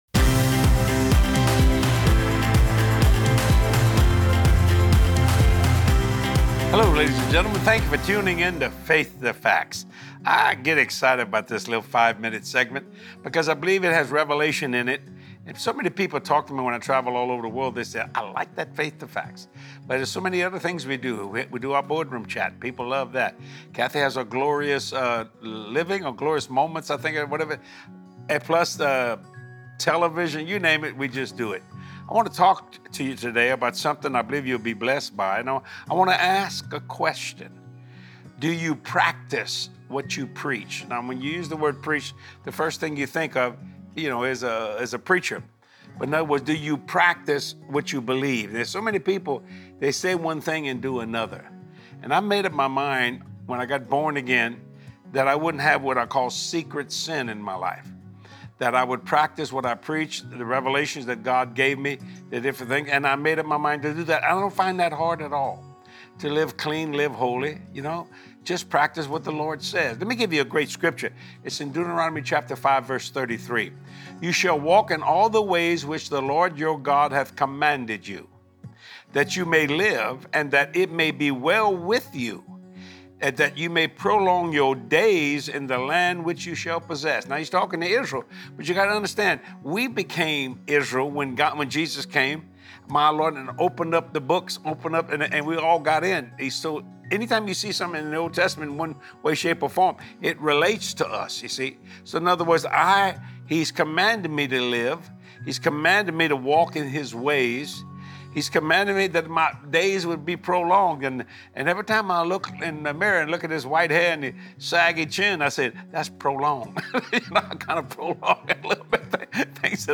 Are you living each day the way the Lord intended? Watch this empowering teaching from decide today to PRACTICE what you preach!